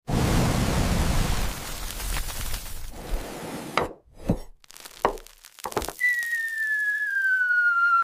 A tornado is approaching. sound effects free download